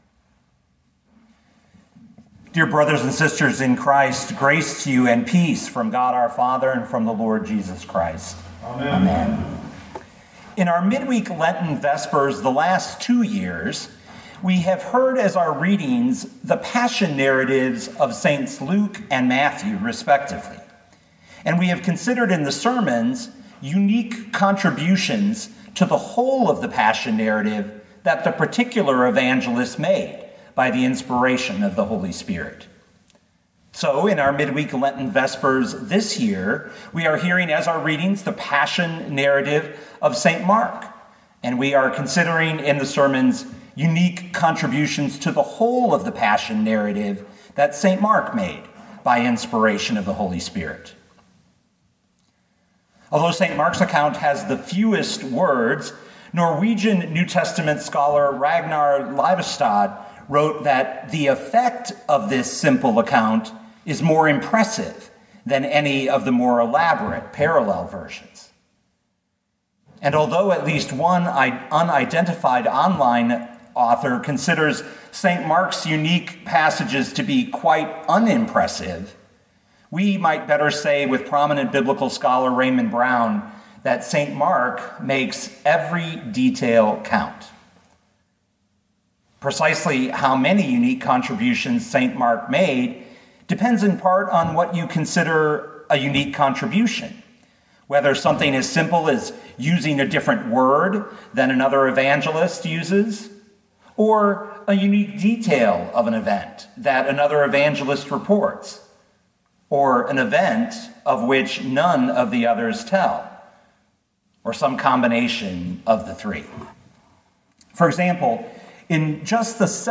2021 Mark 14:7 Listen to the sermon with the player below, or, download the audio.